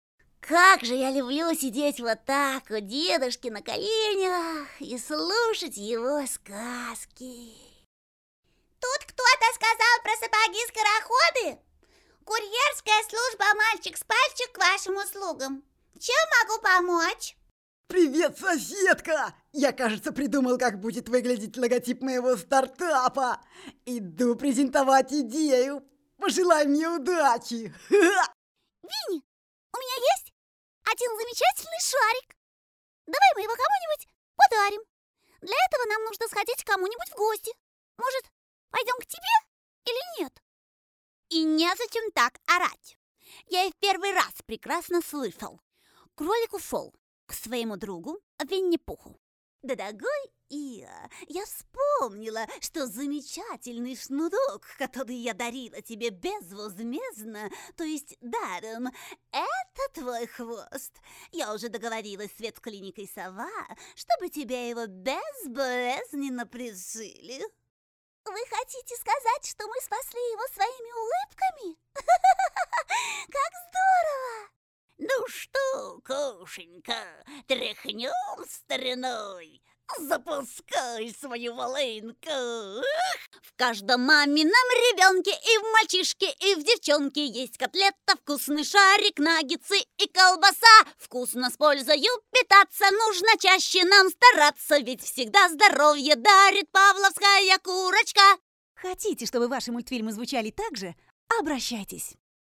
Тракт: Профессиональная студия звукозаписи
Демо-запись №1 Скачать